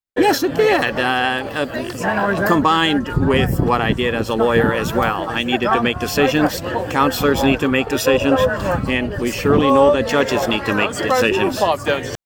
Wednesday, Belleville Mayor Mitch Panciuk along with city councillors and invited guests, honoured the retired Superior Court of Justice judge and former Belleville Council member, with a plaque dedication in the Commons Area on Front Street, across from City Hall.